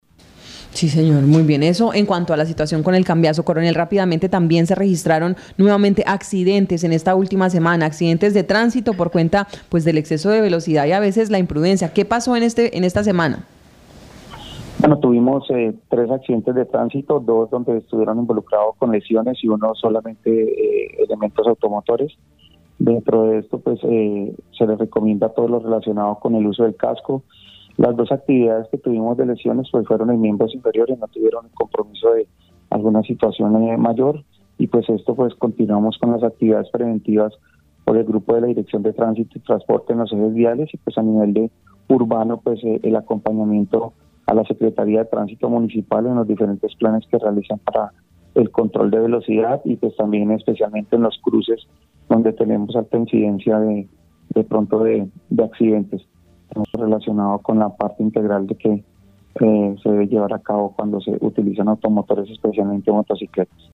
Escuche a coronel Ángel Alexander Galvis, comandante Departamento Policía Guaviare.